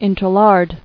[in·ter·lard]